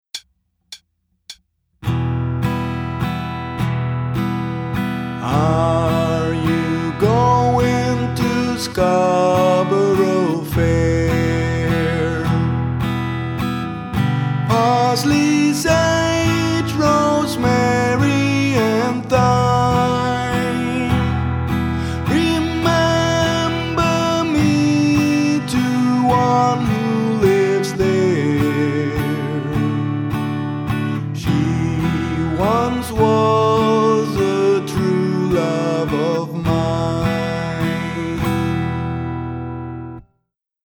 Die 24 Songs wurden alle vom Autor nicht nur mit der Gitarre extra langsam eingespielt, sondern auch eingesungen.